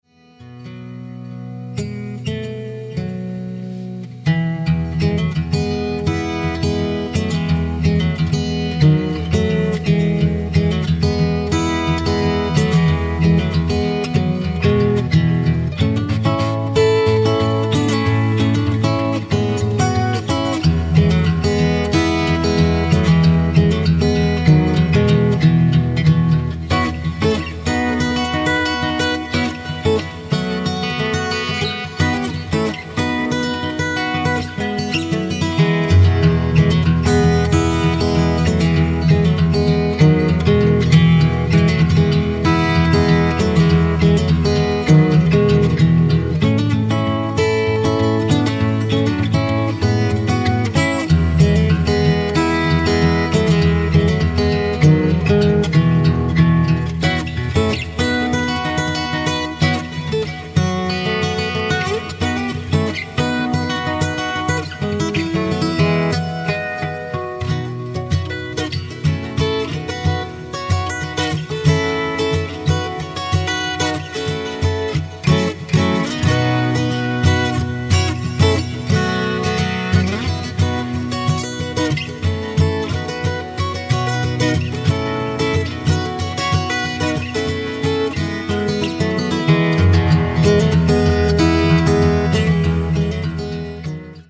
Beautiful instrumental.